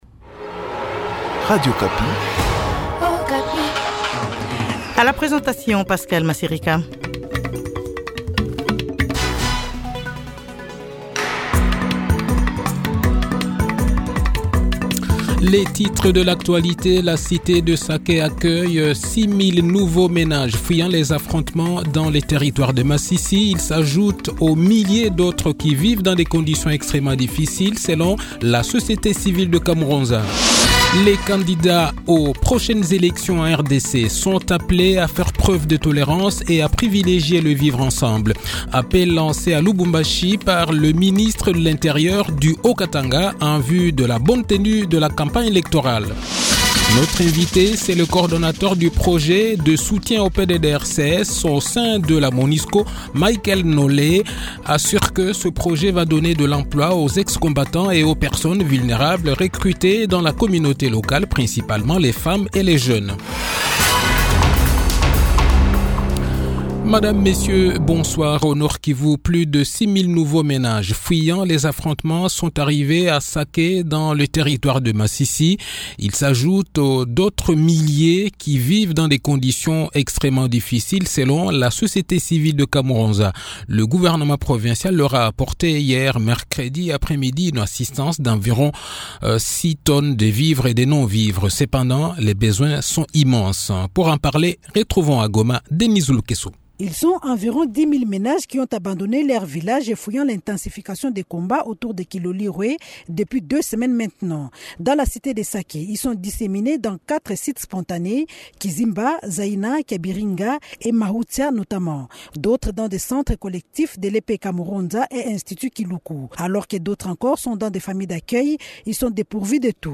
Le journal de 18 h, 30 novembre 2023